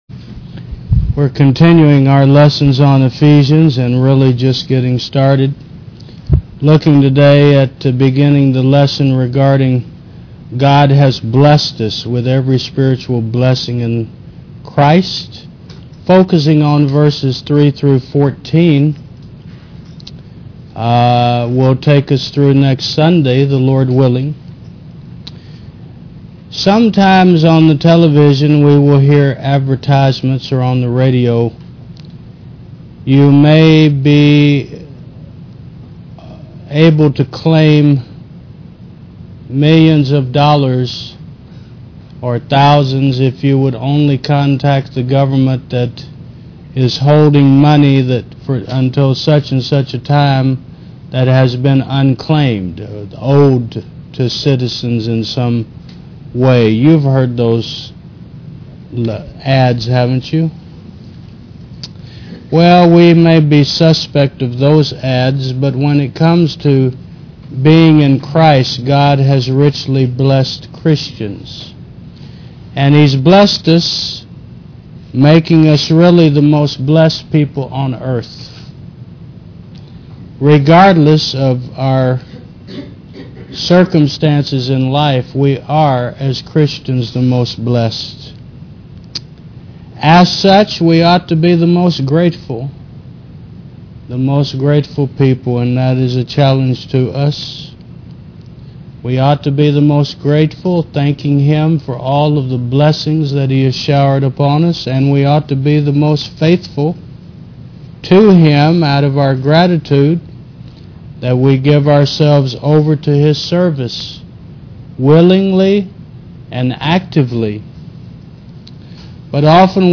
Service Type: Sun. 11 AM